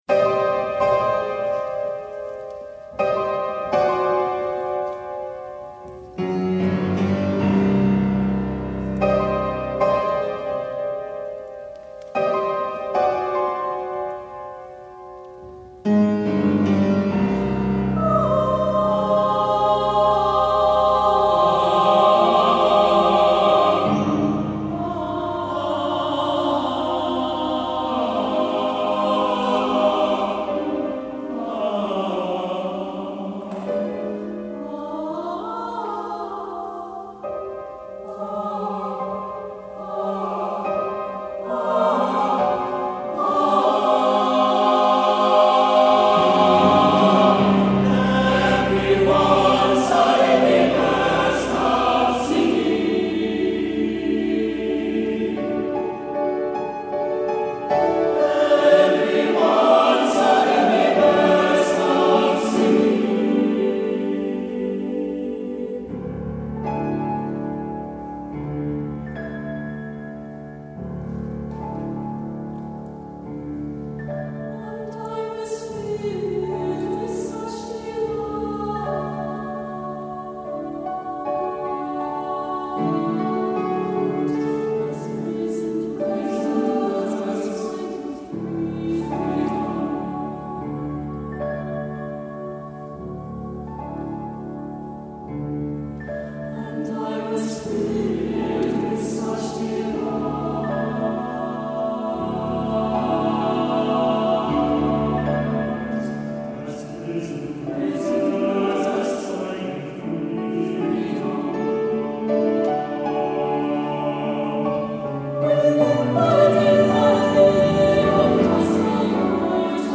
Accompaniment:      With Piano
Music Category:      Choral